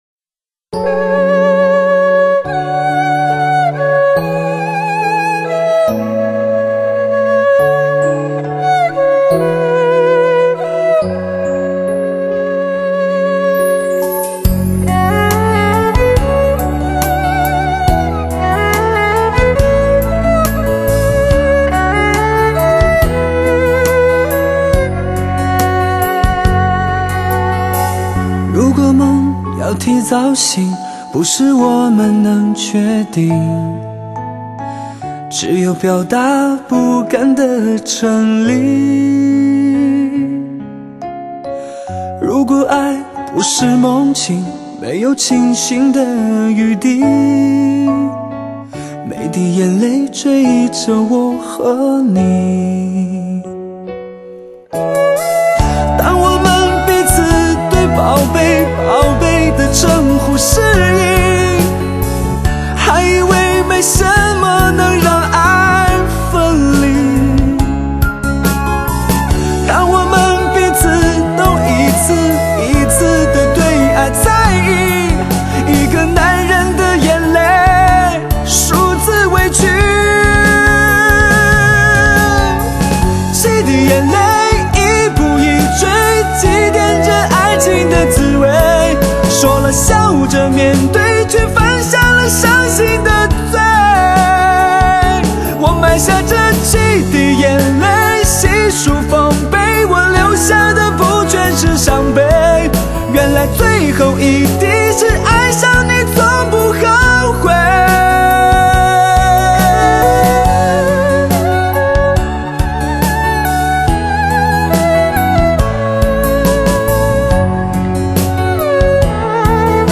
用温婉人声带给您雨的温润、夜的怡人、花的明艳曲目首首经典 现代发烧，声声渗透胃，多么动听的声音！